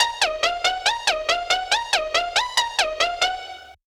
MB Pizzi Loop.wav